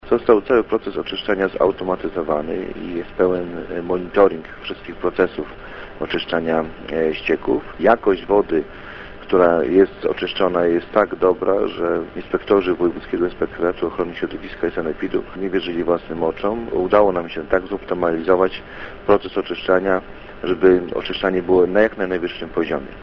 – Zastosowane wówczas technologie wymagały unowocześnienia – mówi Radosław Król, wójt gminy Wydminy.